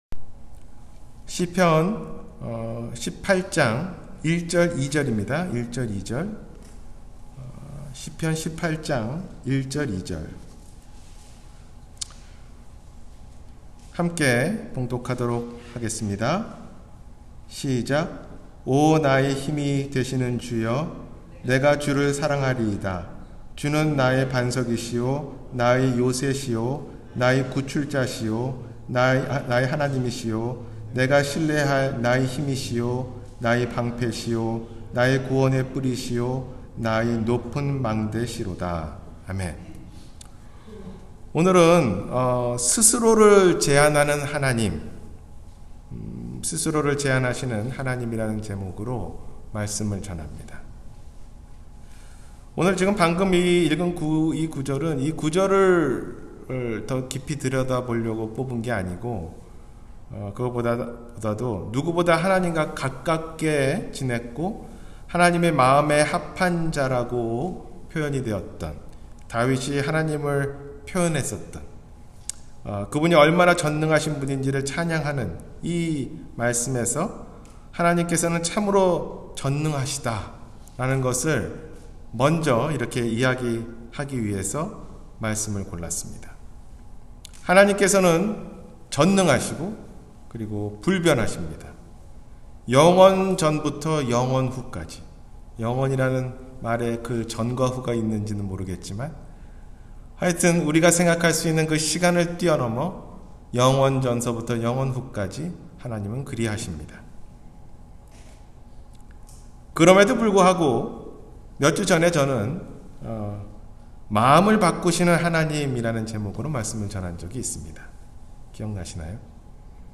스스로를 제한하시는 하나님 – 주일설교